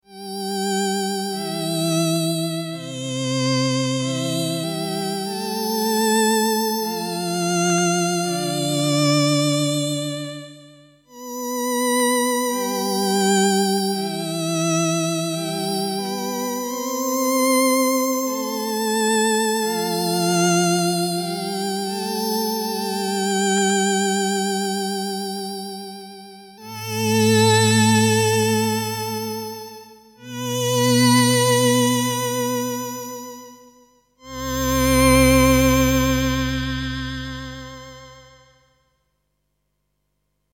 some douphonic